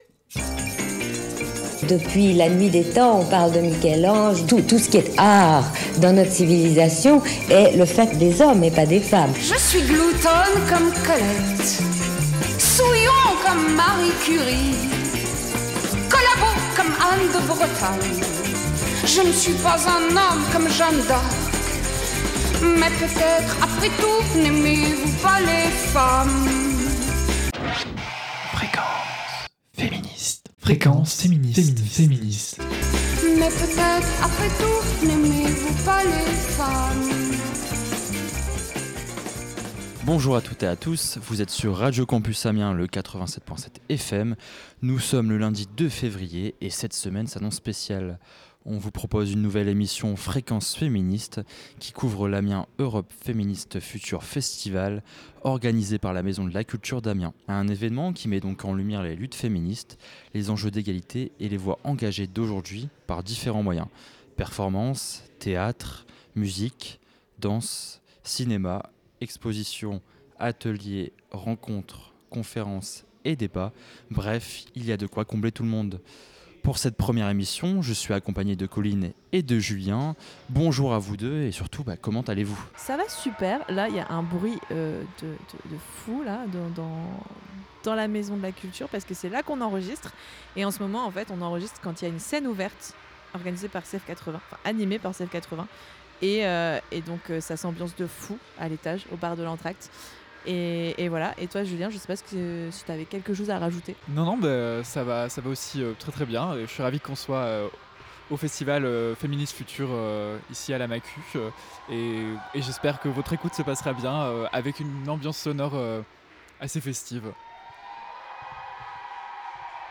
Au programme donc : interviews, chroniques, suggestions littéraires … vous attendent pour profiter avec nous de ces moments de partage et de rencontre.